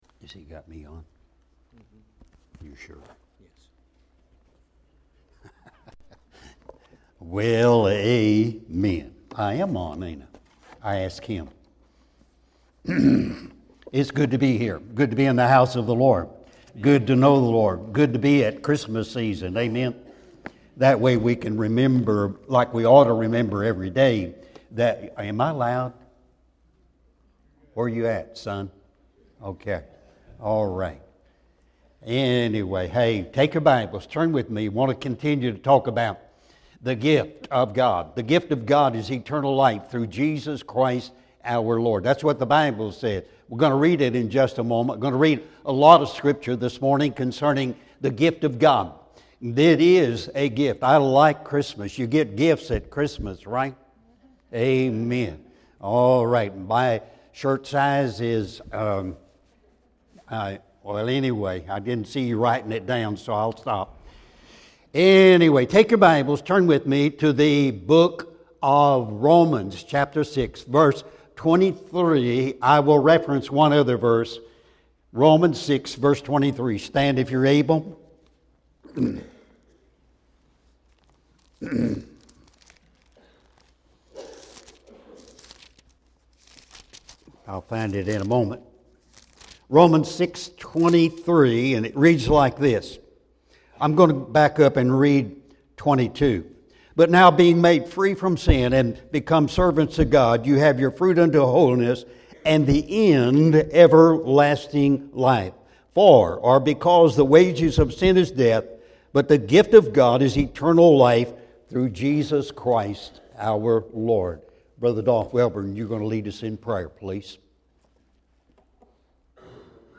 Sunday Sermon December 15, 2019